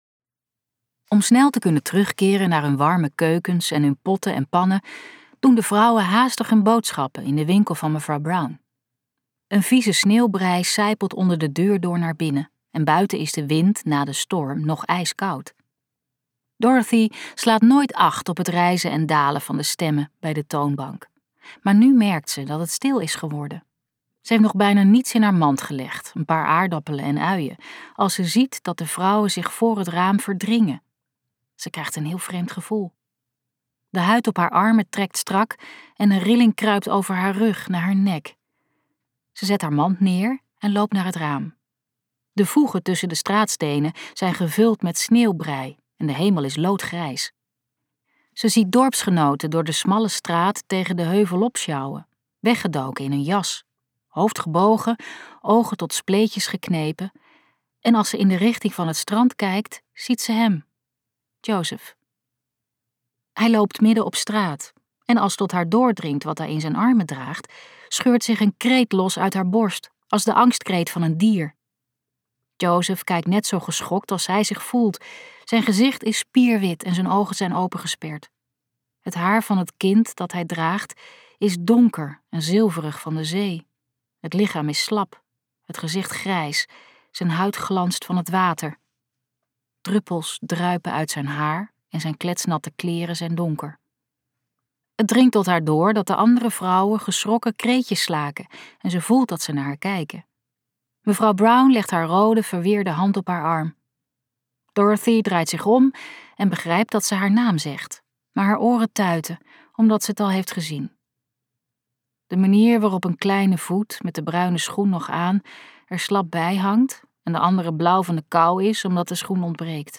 Ambo|Anthos uitgevers - Geschenk van de zee luisterboek